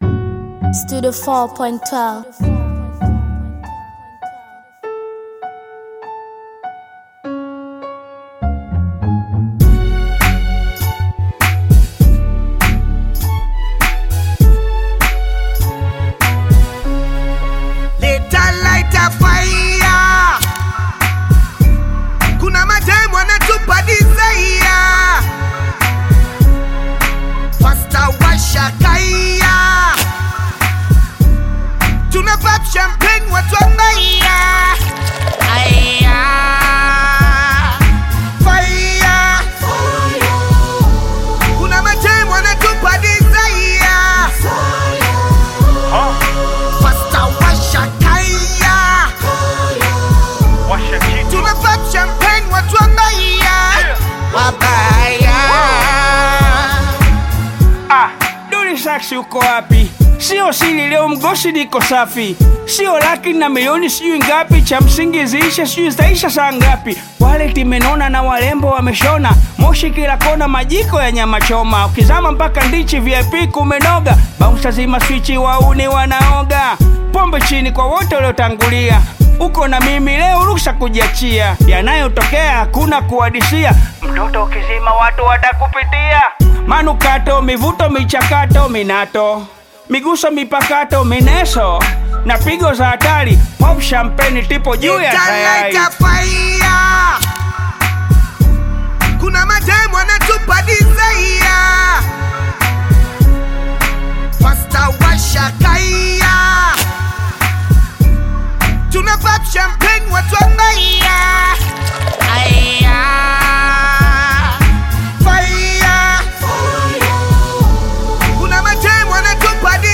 Bongo Flava
Bongo Flava You may also like